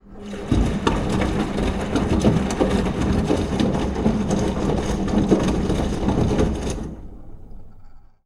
Washing Machine Huge Sound
household